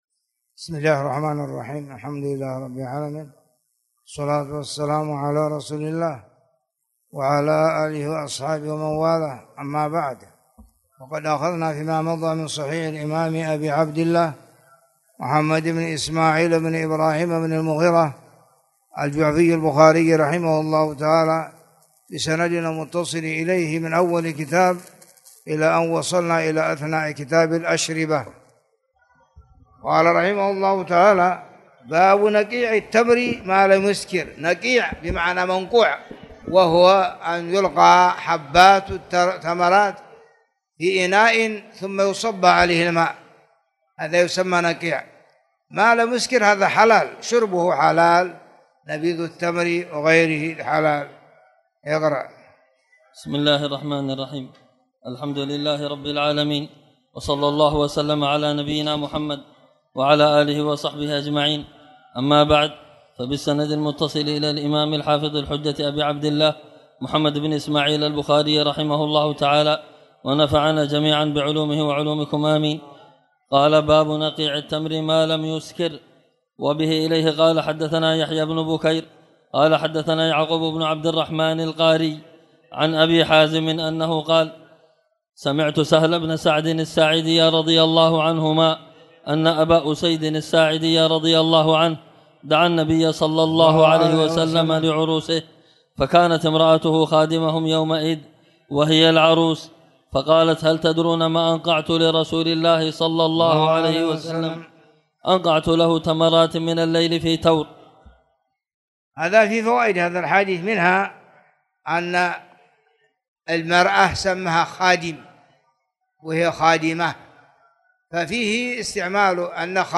تاريخ النشر ٥ ربيع الأول ١٤٣٨ هـ المكان: المسجد الحرام الشيخ